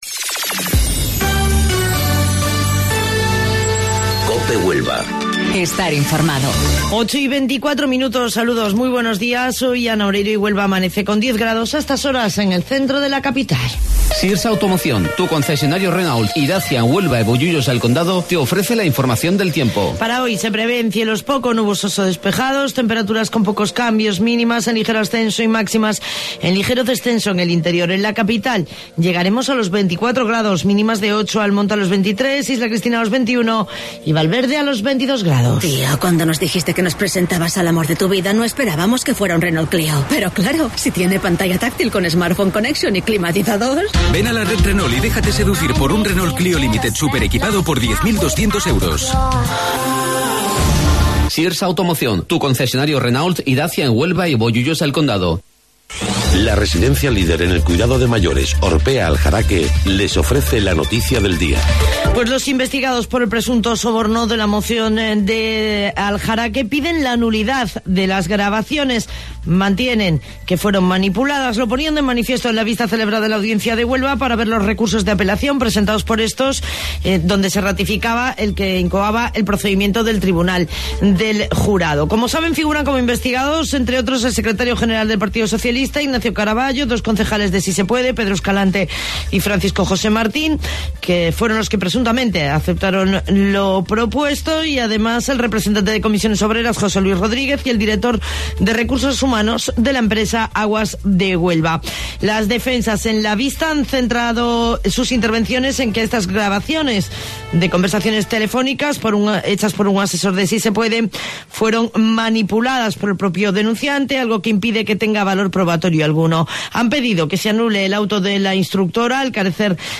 AUDIO: Informativo Local 08:25 del 19 de Marzo